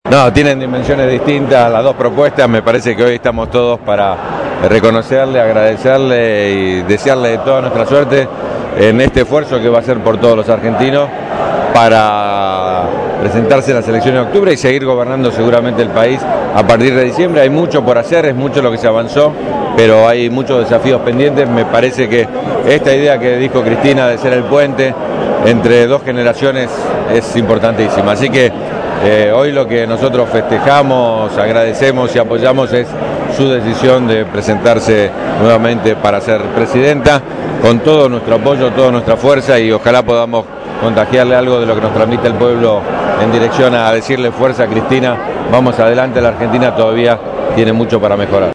registró para los micrófonos de Radio Gráfica FM 89.3 los testimonios de la jornada.